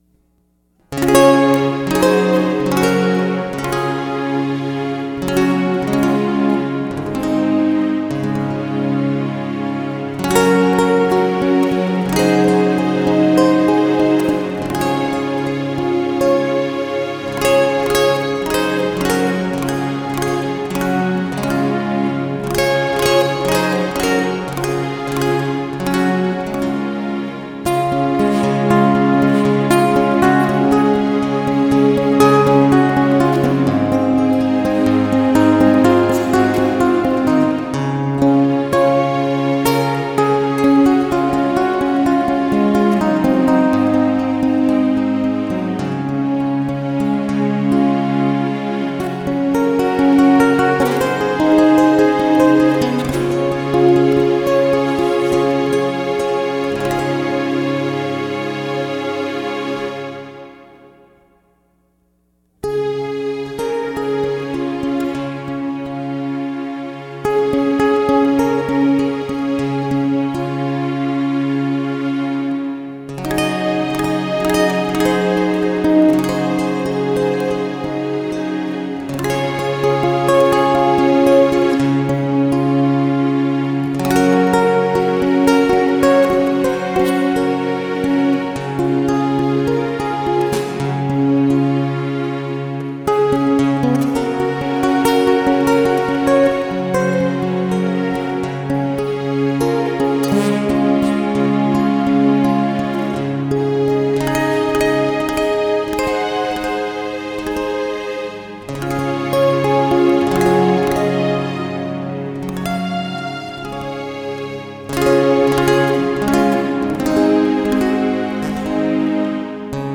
Если я играю ноту или аккорд на гитаре, синтезатор звучит с теми же нотами, просто с другим инструментом (гитара + струны, гитара + орган и т. Д.).
Чтобы продемонстрировать это использование синтезатора, я записал на следующий день или около того после того, как купил GR-20:
rolandGR20-wet.mp3